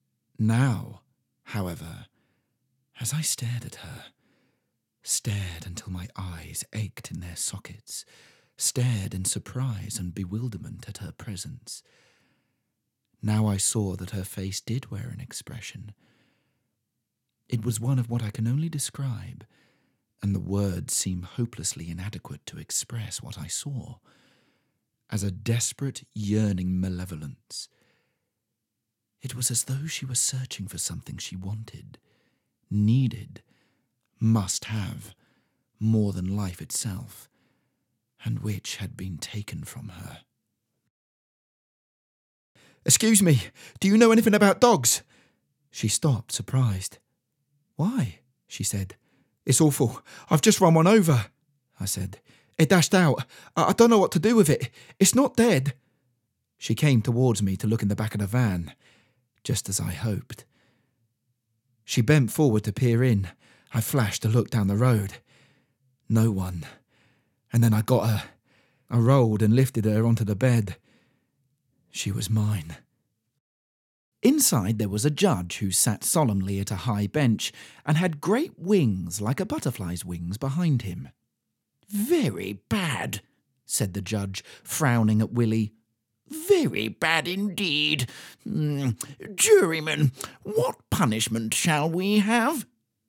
• Native Accent: RP, Welsh
• Home Studio